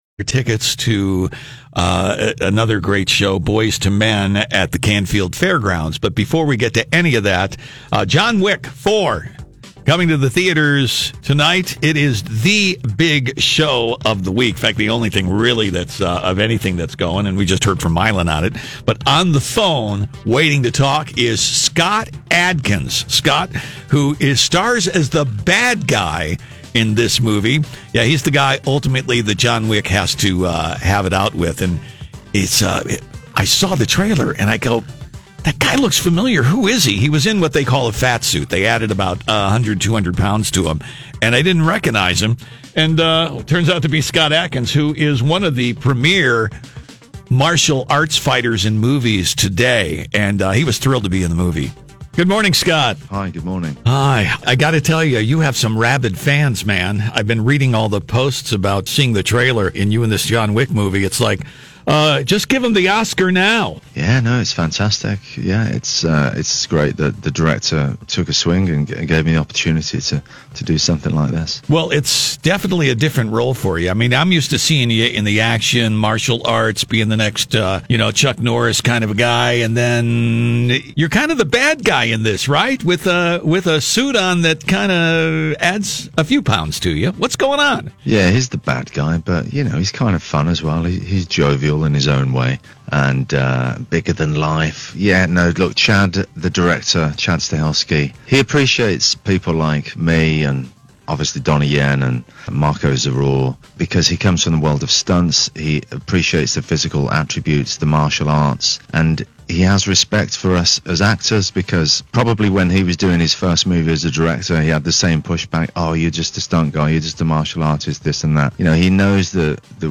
INTERVIEW https